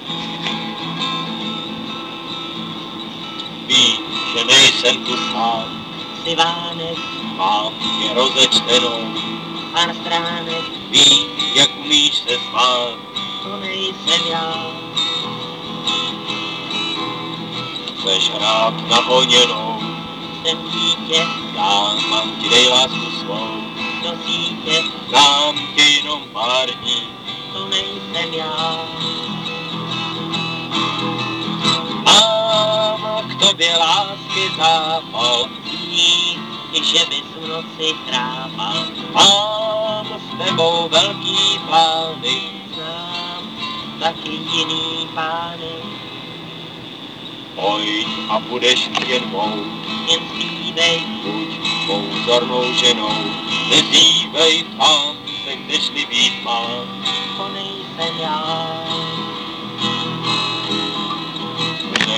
Zpívají píseň.)